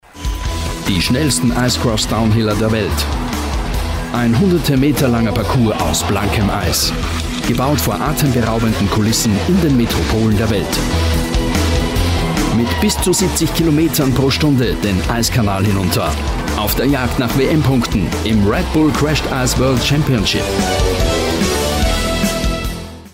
Sprecher, Moderator sowie ISO zertifizierter Kommunikations- u. Performancetrainer.
Sprechprobe: Werbung (Muttersprache):